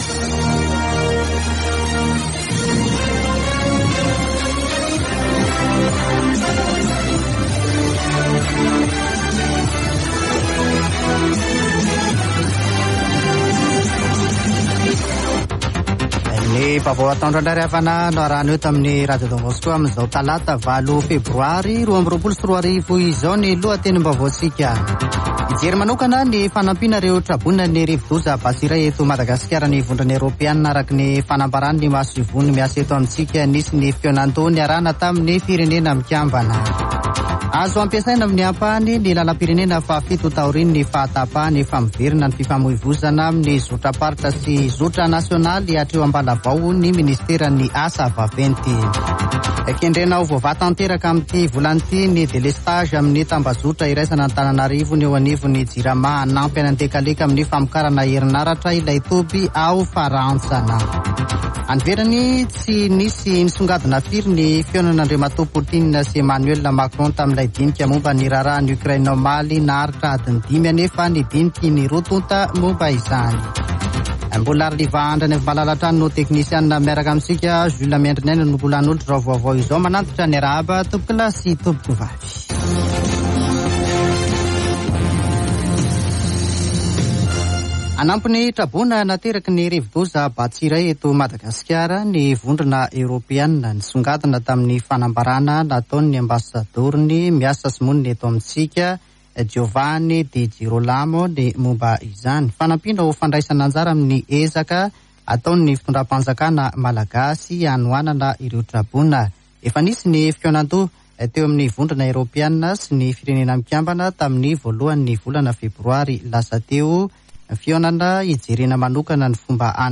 [Vaovao antoandro] Talata 08 febroary 2022